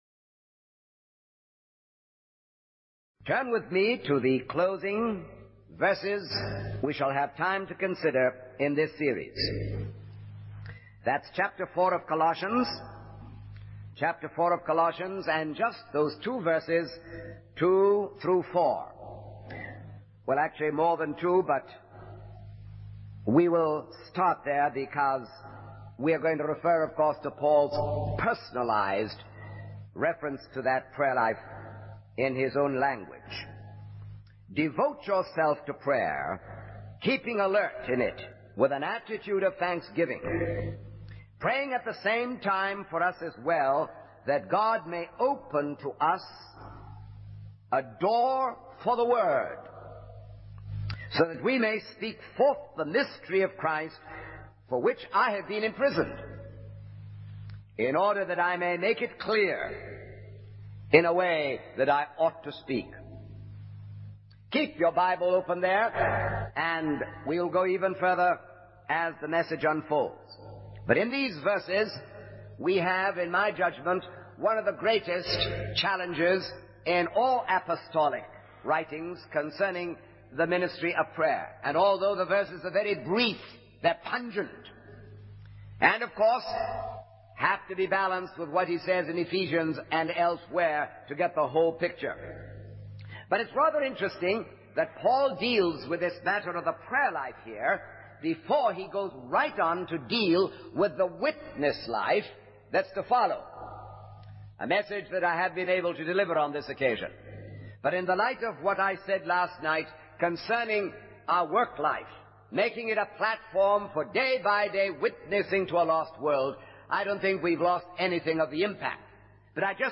In this sermon, the preacher emphasizes the importance of understanding and engaging in prayer. He encourages the congregation to take time to behold God and to speak often with Him. The preacher challenges the listeners to examine their prayer life and commit to a disciplined prayer ministry.